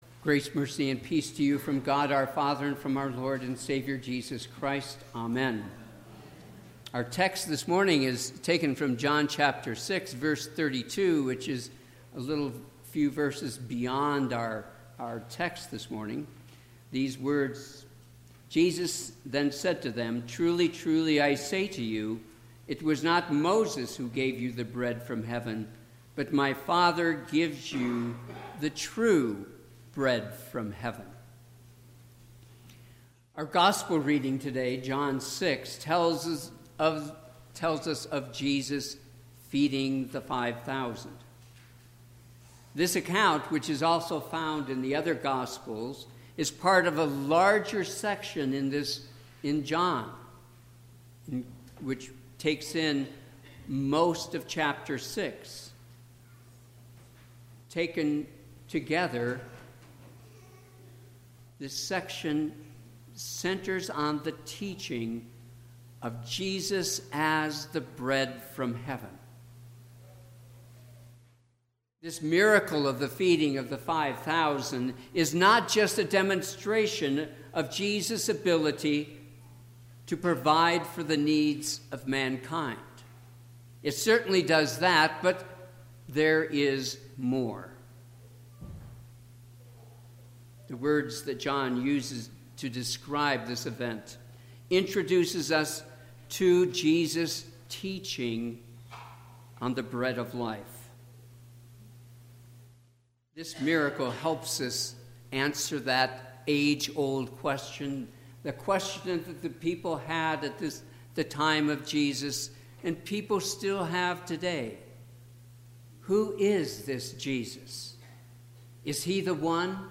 Sermon - 3/31/2019 - Wheat Ridge Lutheran Church, Wheat Ridge, Colorado